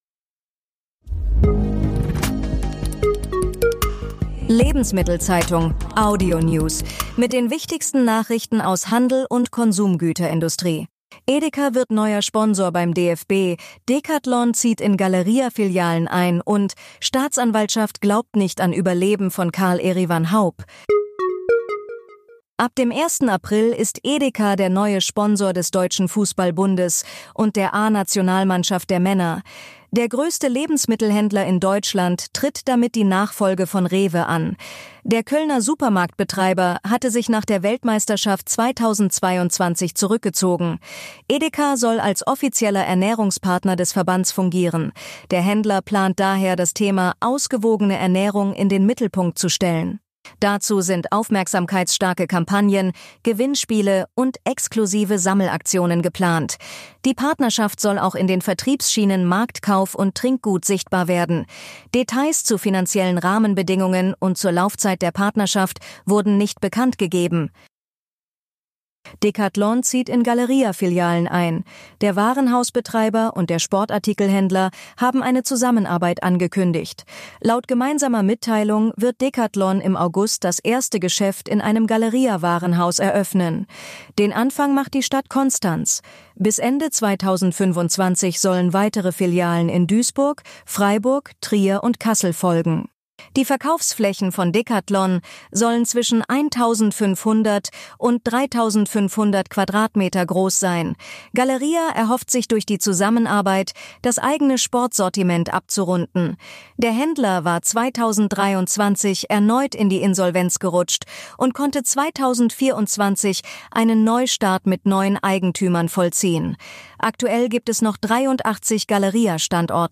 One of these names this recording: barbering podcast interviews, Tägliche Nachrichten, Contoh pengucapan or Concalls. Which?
Tägliche Nachrichten